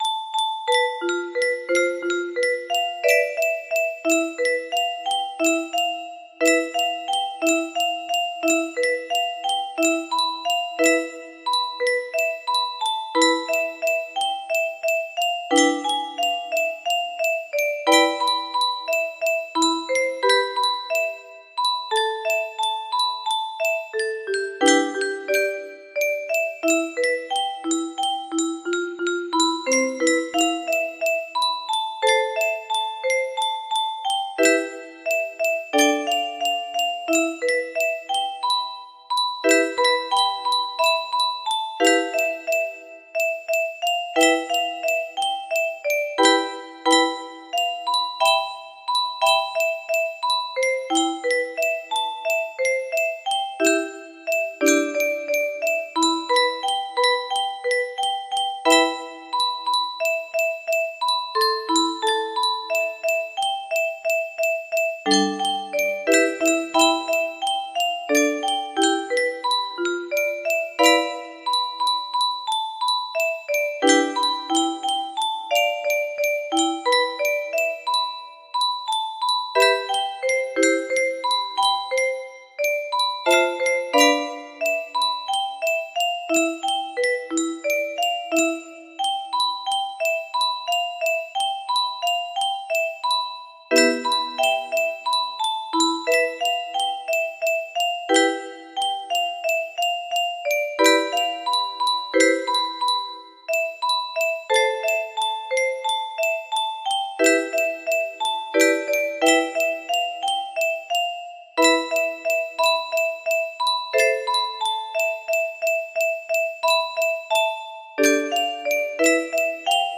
Imported from MIDI from imported midi file (7).mid